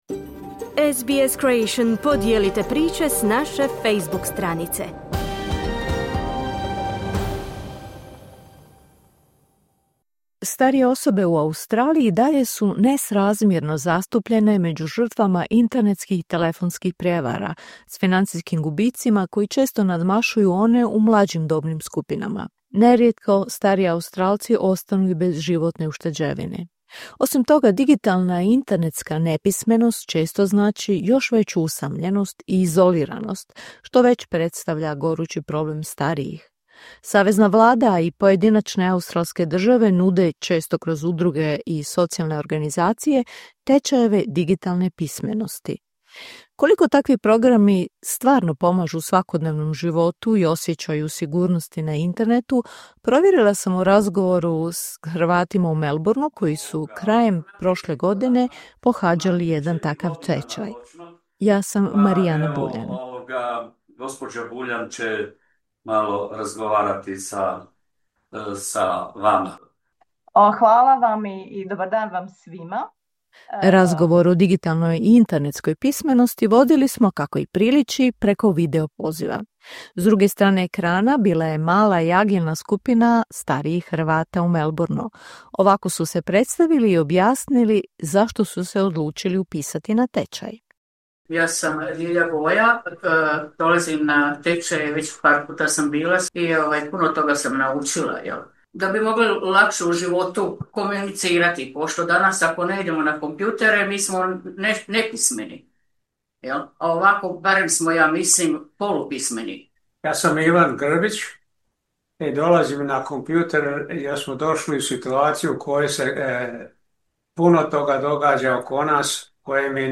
Koliko takvi programi doista pomažu u svakodnevnom životu i osjećaju sigurnosti na internetu, te imaju li umirovljenici oslonac u zajednici i obiteljima otkrivaju Hrvati iz Meloburnea.